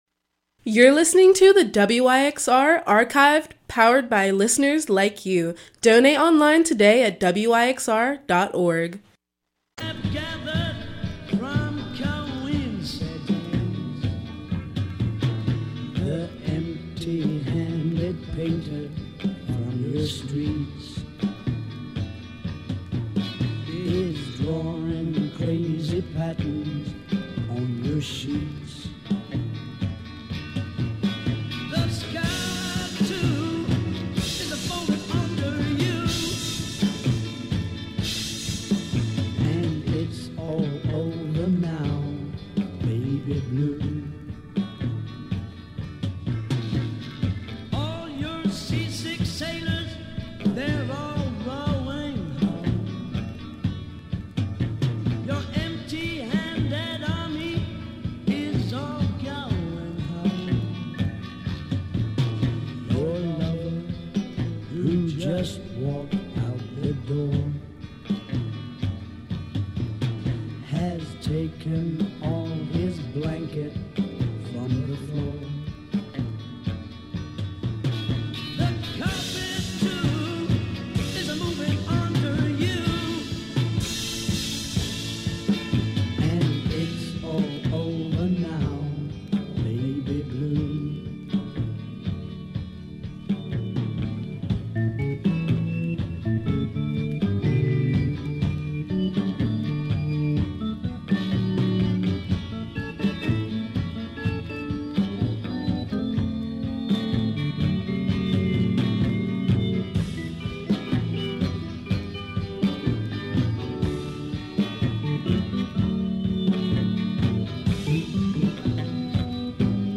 Funk Soul Hip Hop